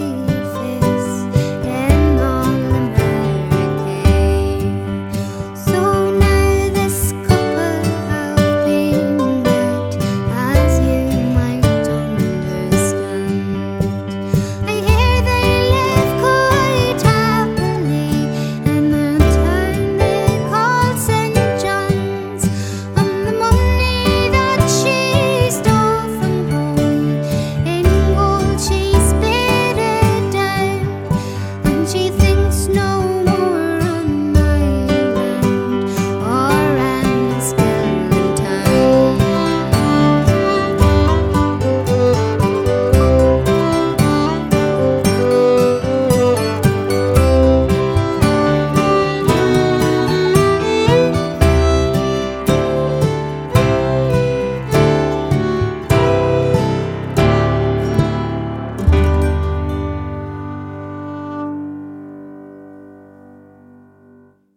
Fiddle/vocals/Glockenspiel
Piano/Piano Accordion